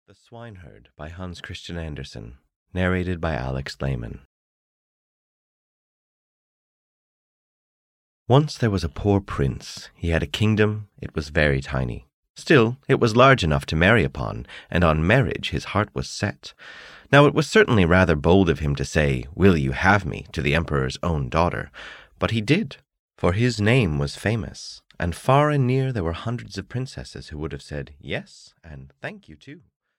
The Swineherd (EN) audiokniha
Ukázka z knihy